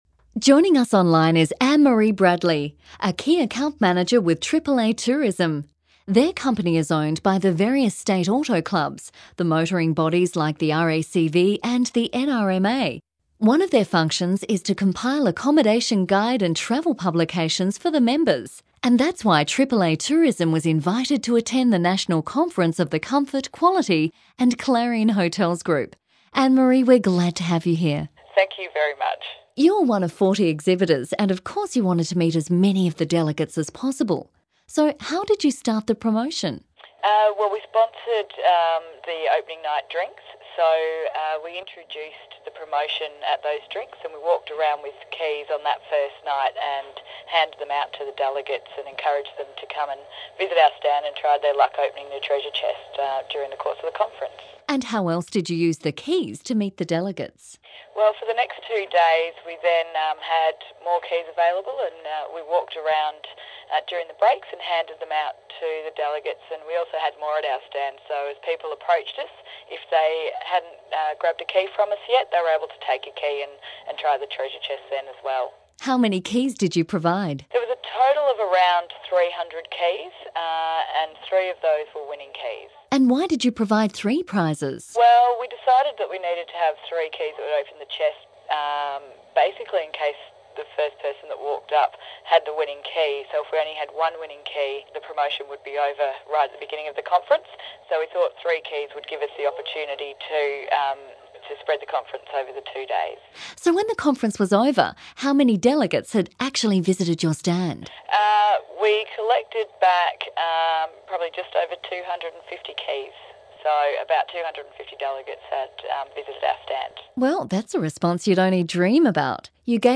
Below are audio interviews recorded with our clients explaining effective sales promotions campaigns.